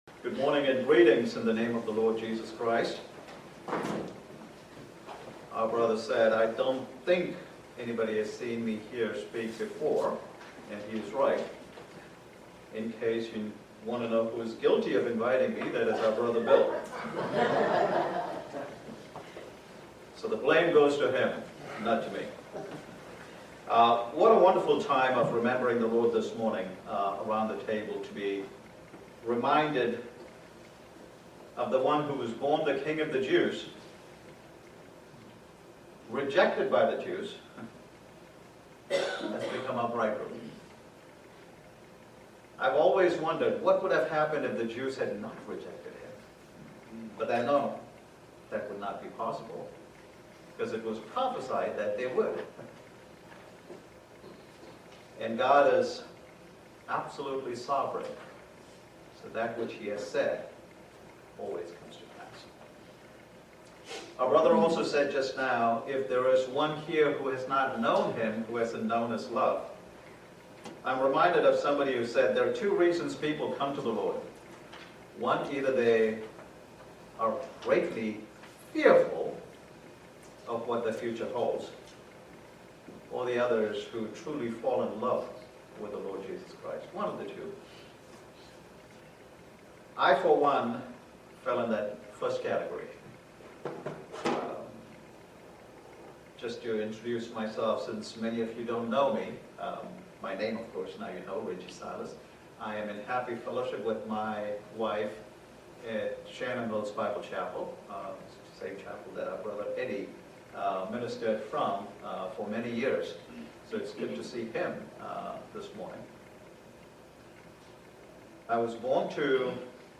Isaiah 42:1-9 Service Type: Family Bible Hour Jesus is the Perfect Servant